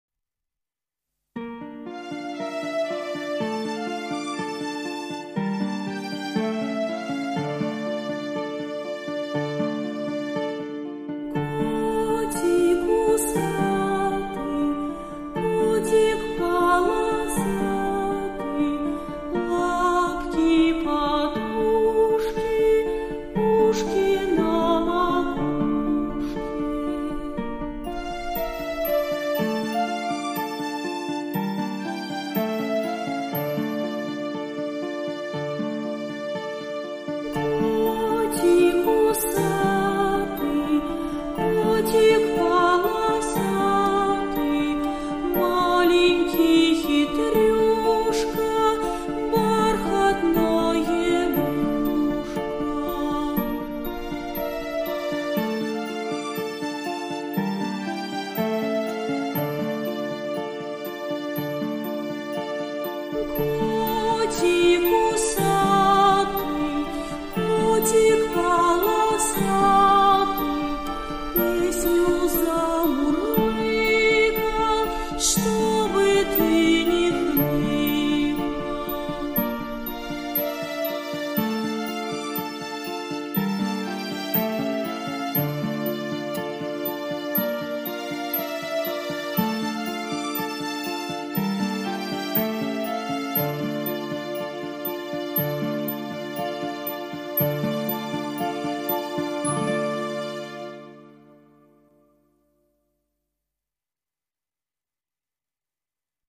Котик - песенка про животных - слушать онлайн